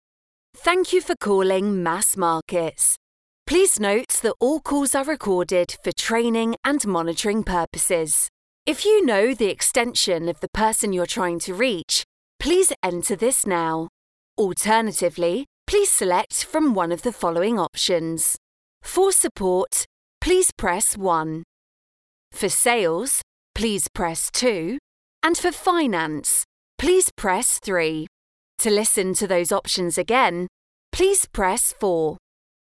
Sennheiser MK4
Jovem adulto
Mezzo-soprano